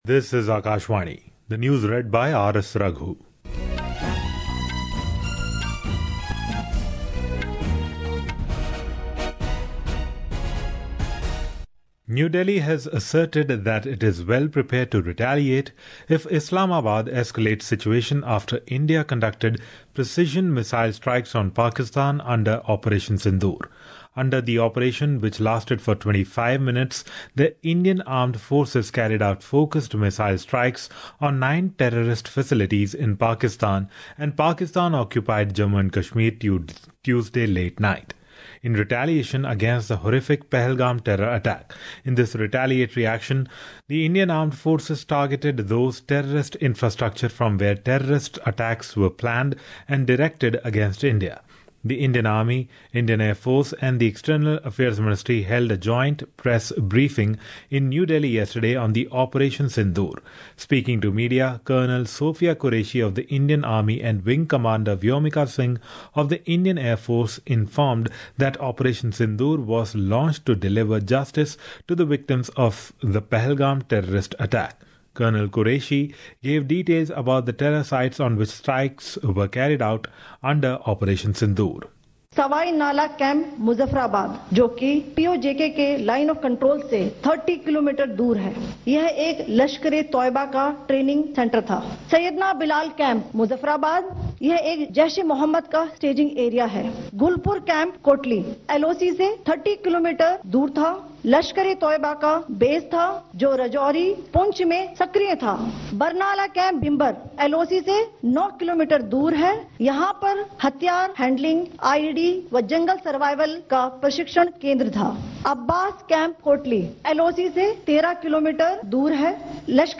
Hourly News | English